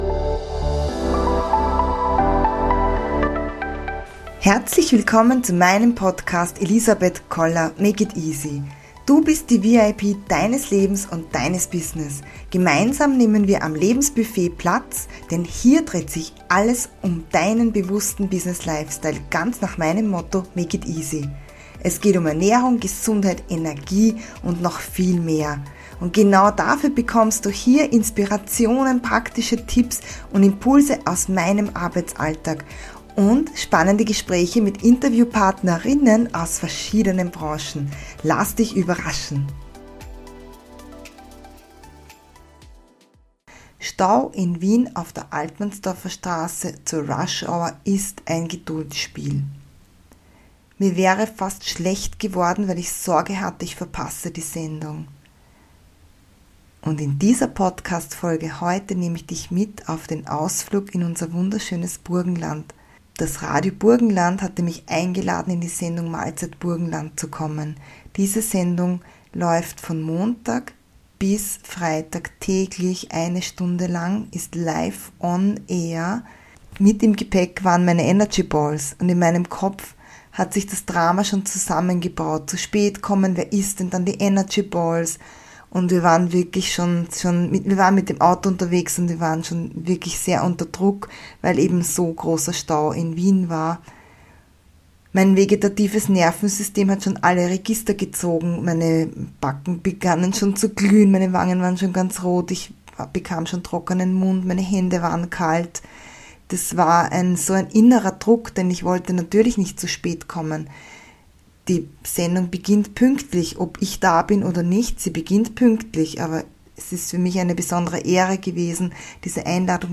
Zu Gast im Radio bei "Mahlzeit Burgenland"